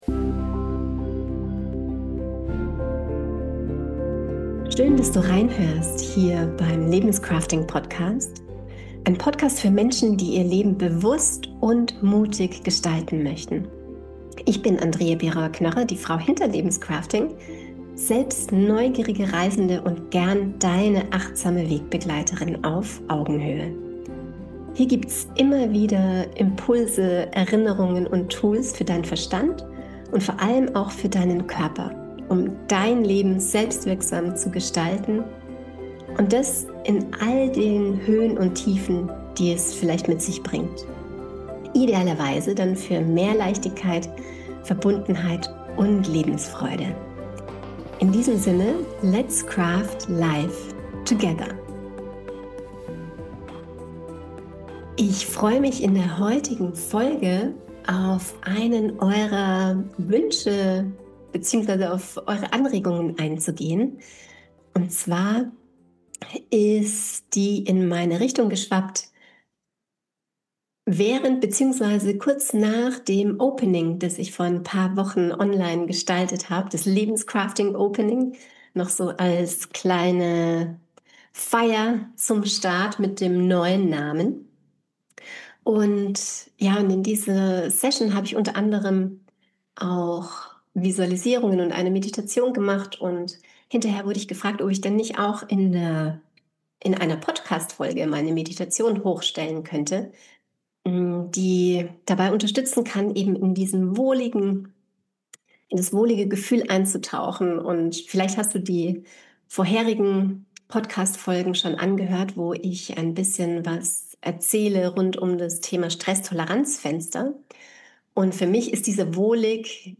In dieser Folge teile ich eine sanfte Meditation, die dich beim Tagesausklang begleiten kann. Wir lassen den Tag Revue passieren und tauchen in ein Gefühl der Verbundenheit ein.
Es gibt kein Outro, sodass du nach der Meditation sanft in den Abend oder vielleicht sogar Schlaf hineingleiten kannst.